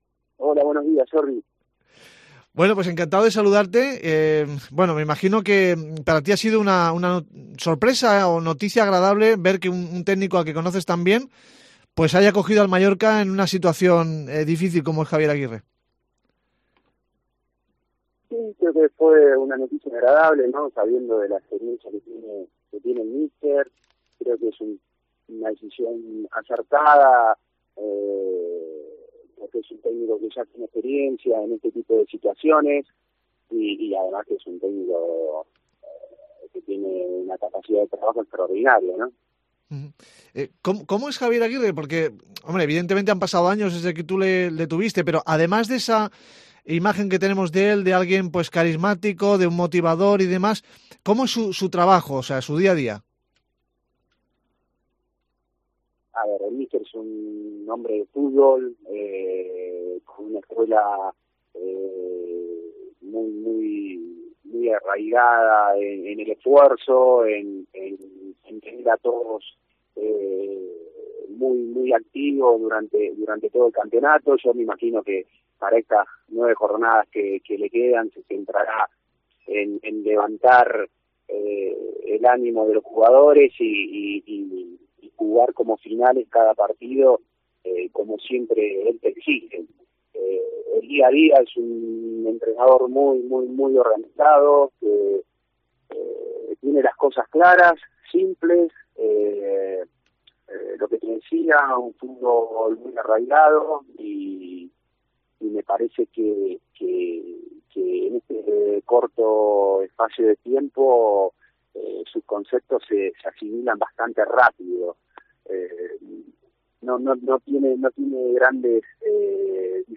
Leo Franco tuvo a Javier Aguirre como entrenador en el Atlético de Madrid. Hablamos con el ex meta argentino sobre cómo es Aguirre como técnico.